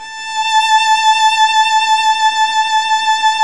Index of /90_sSampleCDs/Roland - String Master Series/STR_Violin 2&3vb/STR_Vln2 mf vb